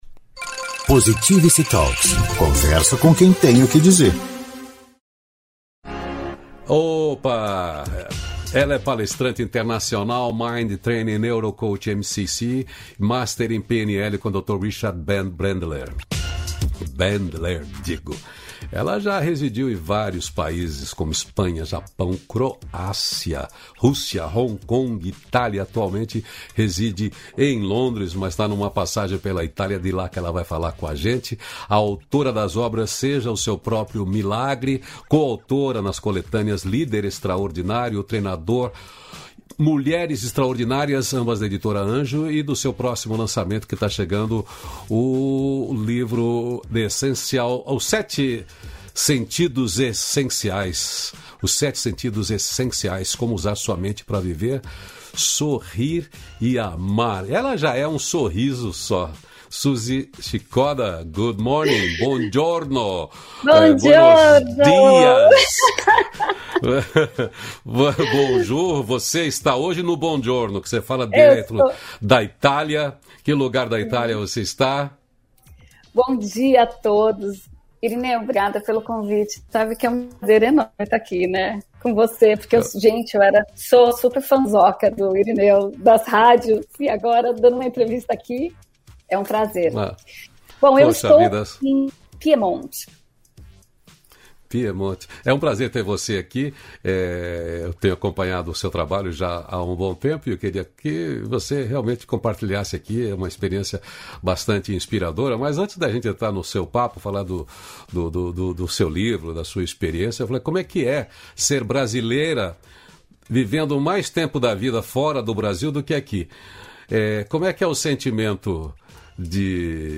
290-feliz-dia-novo-entrevista.mp3